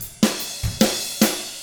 146ROCK F2-R.wav